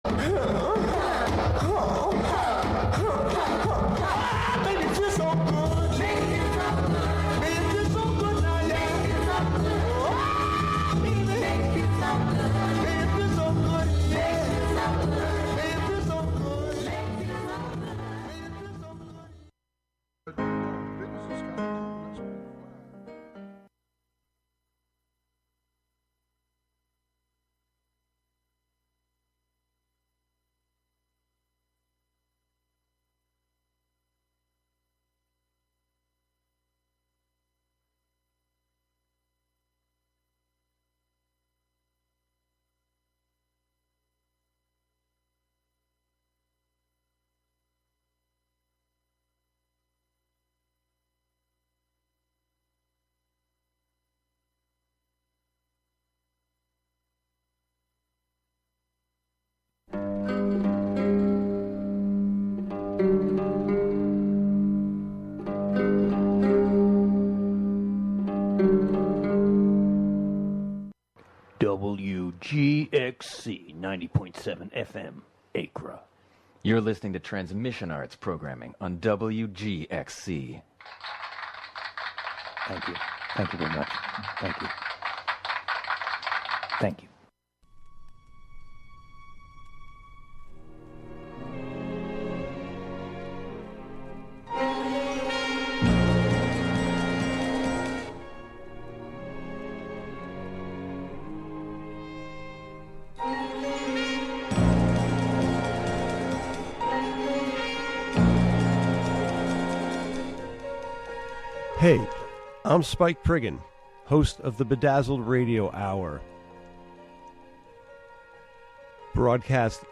short stories and fables from around the world that are available in print will be the only topic here. i won’t offer commentary or biographies on-air. just the fictions read for the listener as best as i can read them.